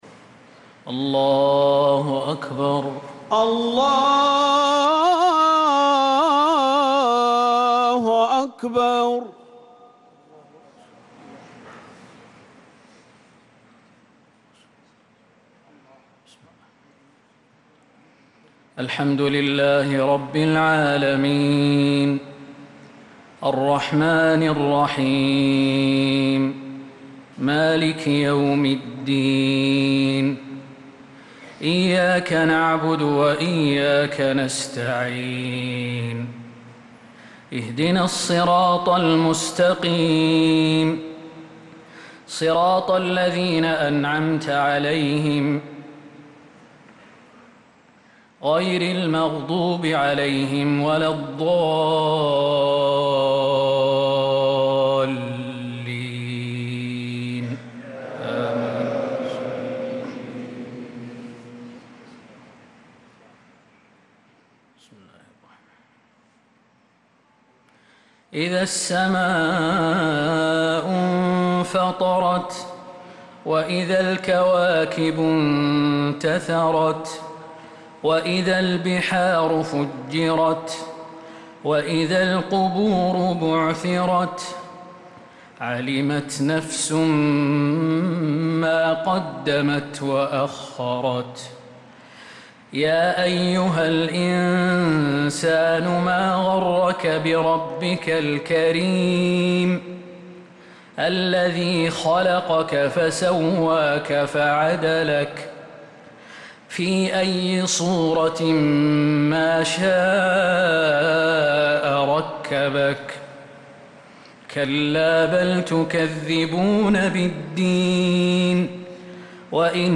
صلاة العشاء للقارئ خالد المهنا 14 ربيع الآخر 1446 هـ
تِلَاوَات الْحَرَمَيْن .